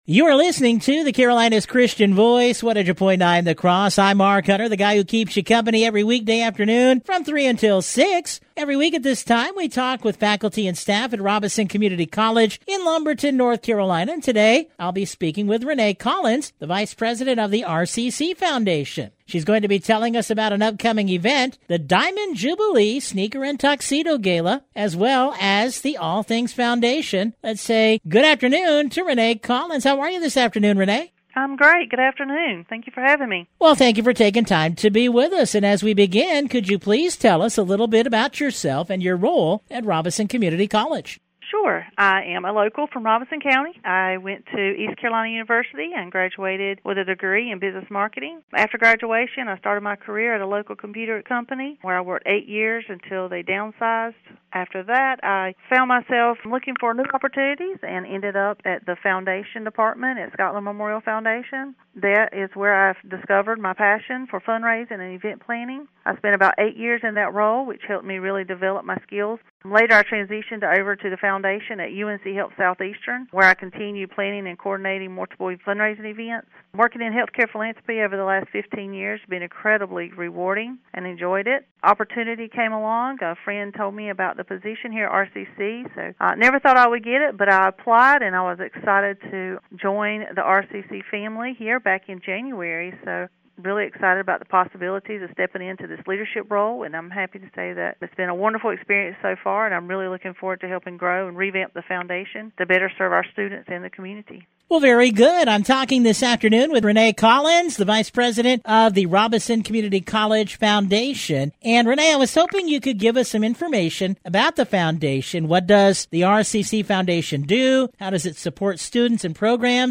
A major highlight of the interview was the Diamond Jubilee Sneaker and Tuxedo Ball, an elegant yet fun-filled gala that marks the official kickoff of Robeson Community College’s 60th Anniversary celebration.